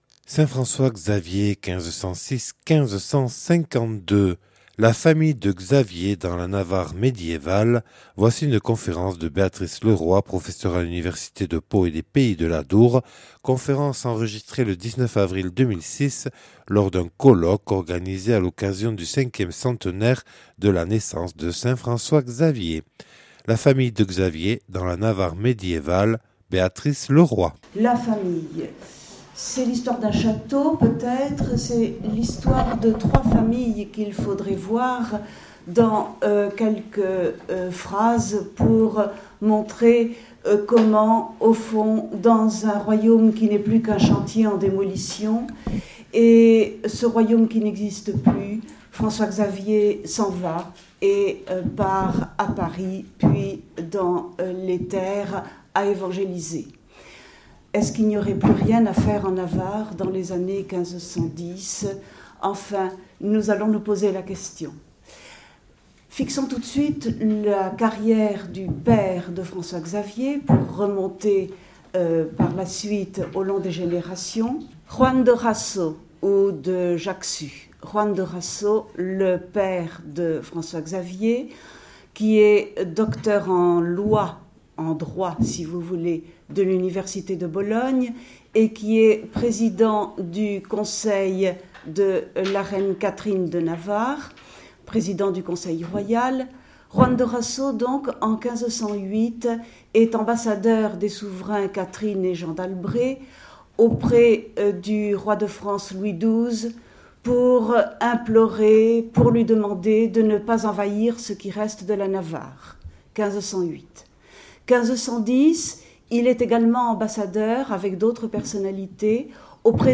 (Enregistrée le 19/04/2006 à Bayonne lors du colloque du 5ème centenaire de la naissance de saint François-Xavier (1506-1552).